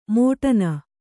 ♪ mōṭana